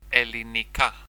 Pronunciation[eliniˈka]